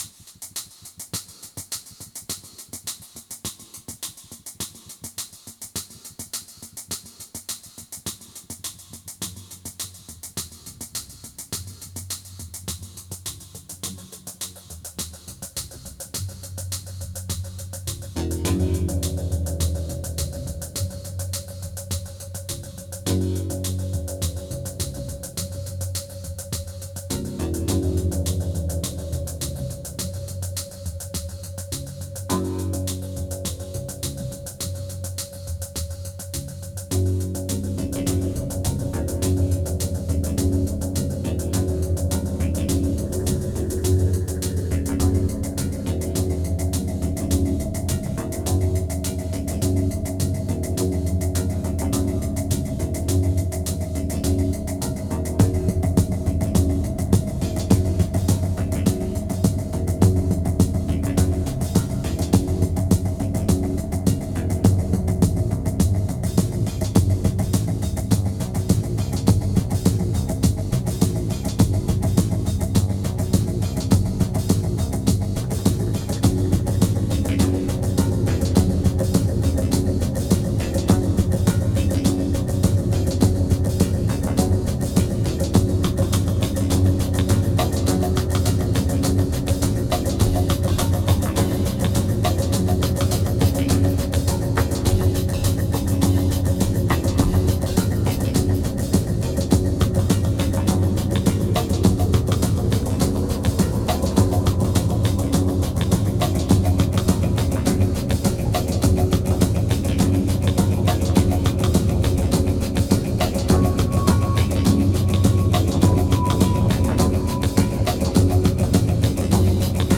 2007📈 - 28%🤔 - 104BPM🔊 - 2010-12-30📅 - -103🌟